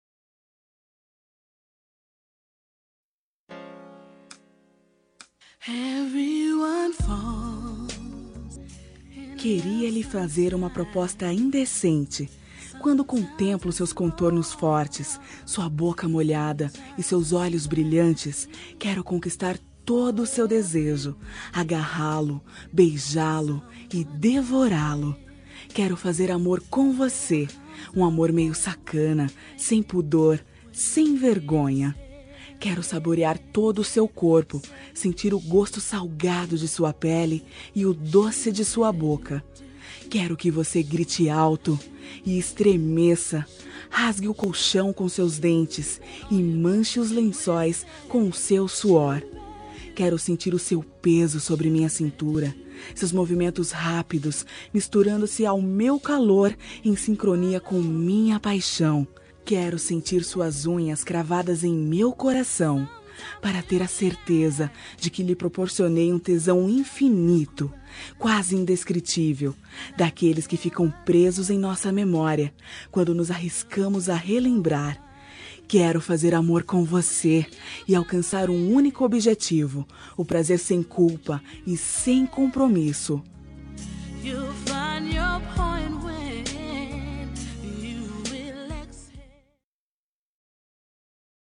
Telemensagem Picante – Voz Feminina – Cód: 79213